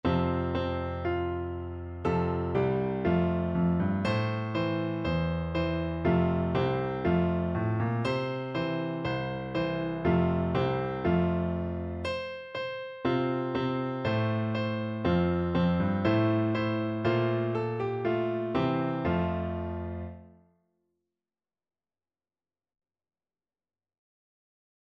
World Asia China Ba luobo (Pull the Carrots)
Free Sheet music for Piano Four Hands (Piano Duet)
2/2 (View more 2/2 Music)
F major (Sounding Pitch) (View more F major Music for Piano Duet )
Steadily =c.120